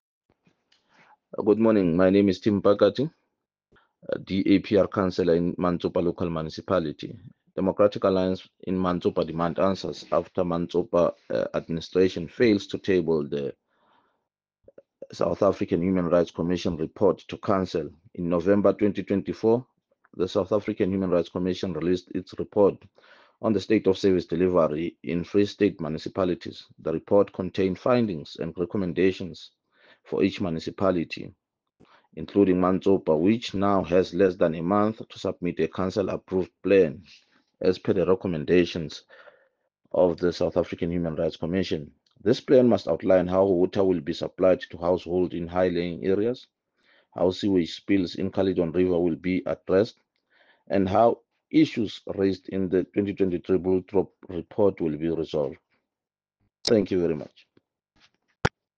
Sesotho soundbites by Cllr Tim Mpakathe.